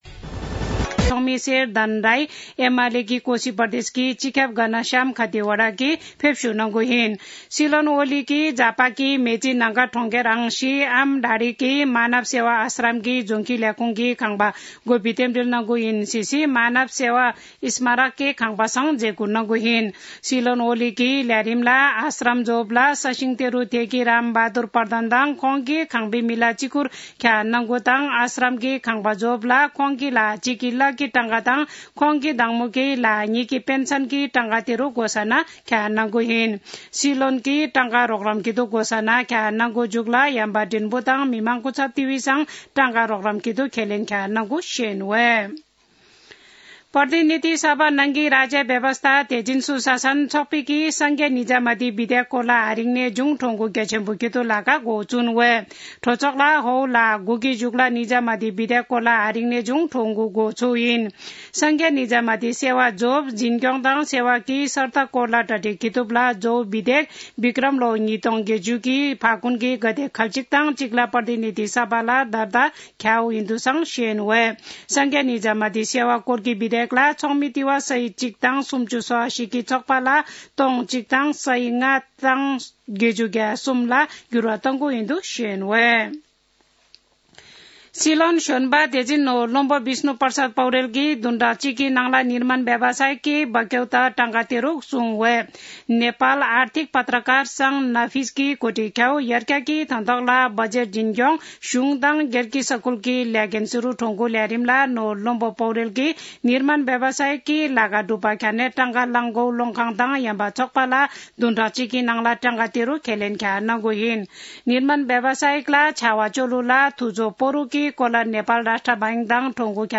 शेर्पा भाषाको समाचार : २४ मंसिर , २०८१
Sherpa-News-23.mp3